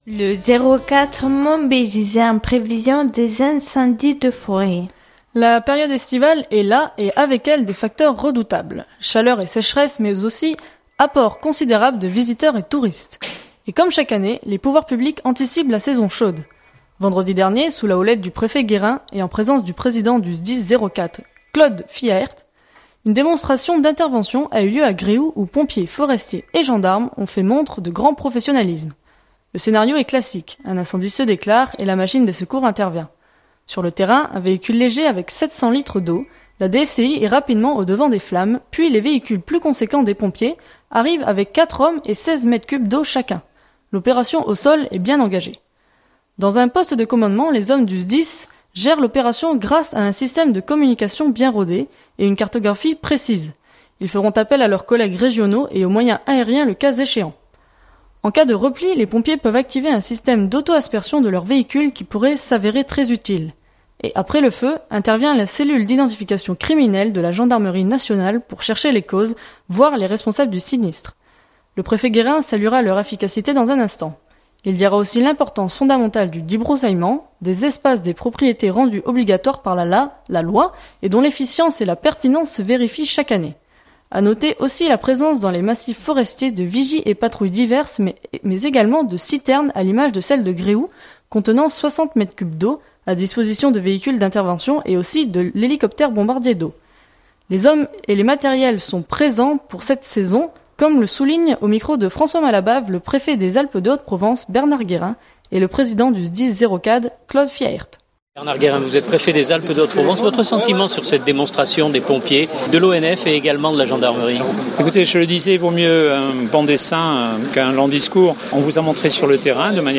Journal du 2017-06-26 - Prévention Incendies - Manosque.mp3 (3.06 Mo)
Le Préfet Guérin saluera leur efficacité dans un instant. Il dira aussi l’importance fondamentale du débroussaillement des espaces et propriétés rendu obligatoire par la loi et dont l’efficience et la pertinence se vérifient chaque année.